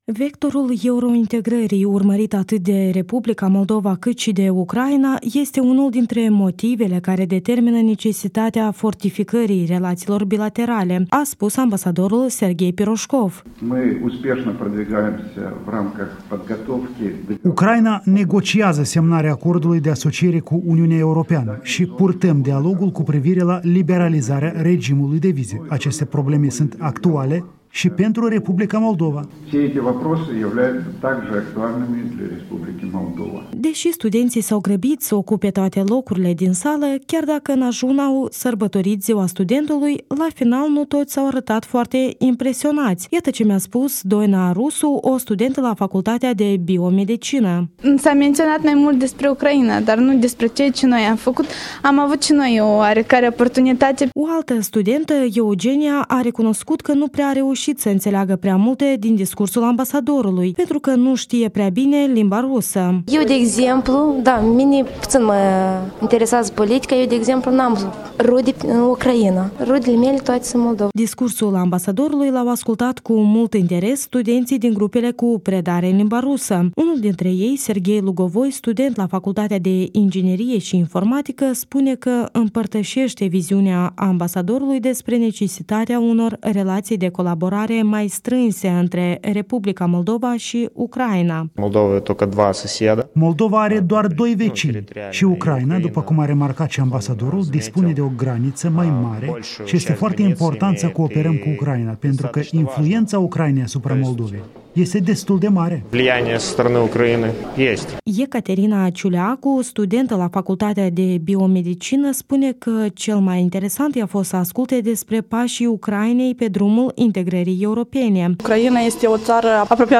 Serghei Pirojkov, ambasadorul Ucrainei la Chişinău în fața studenților.
Ambasadorul Ucrainei în Moldova vorbind studenților de la ULIM